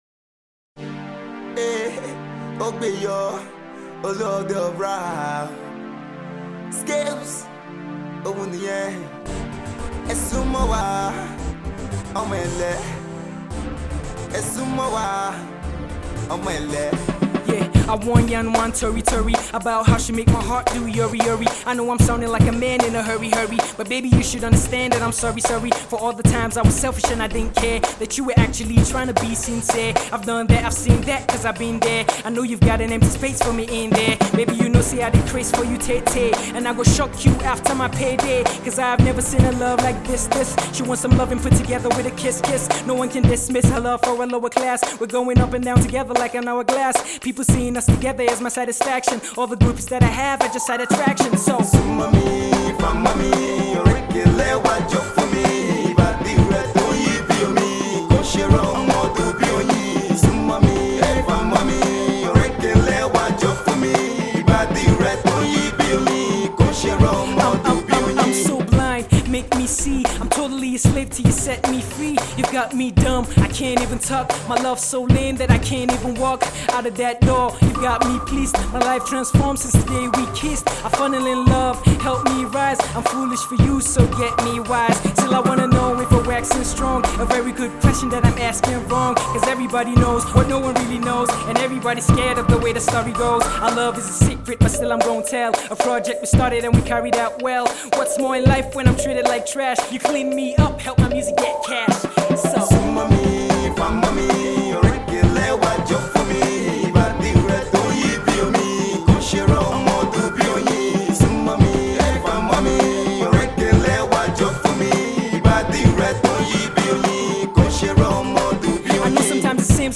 On this new rap cut
witty lyrical wordplay